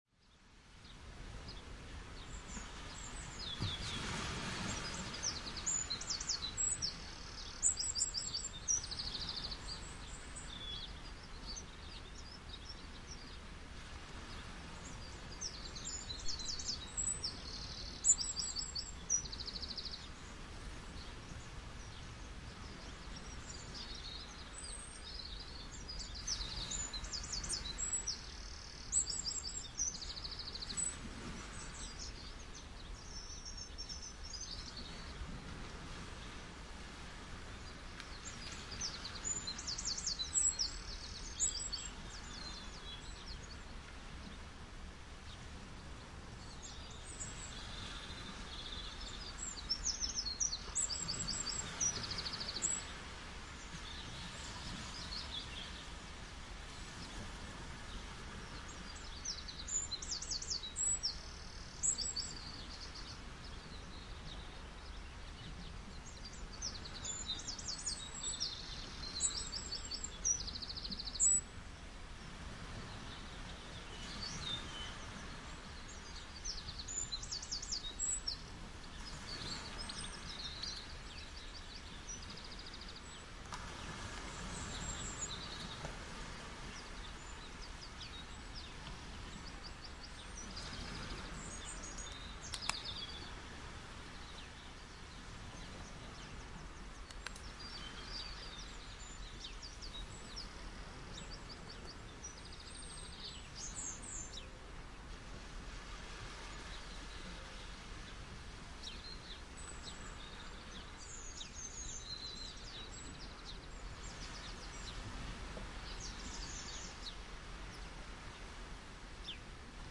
gentle-ocean-and-birdsong-24068.mp3